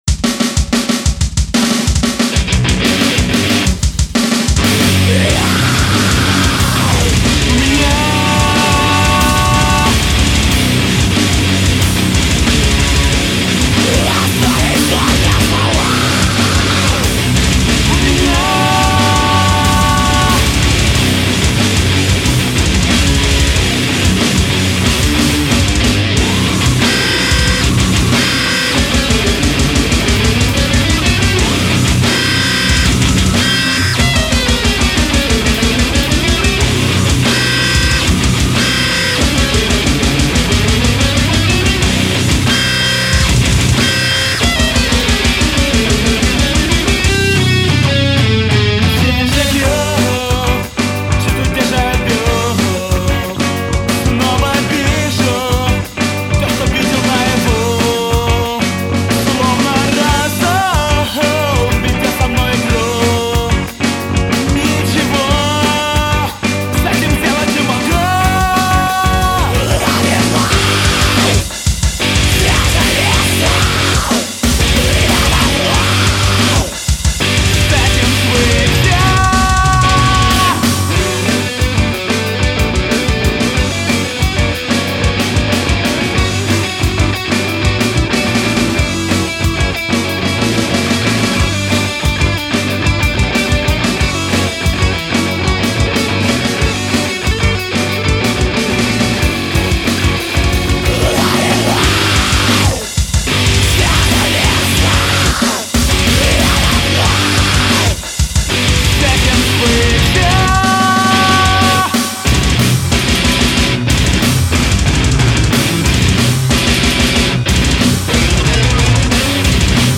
как progressive math metal.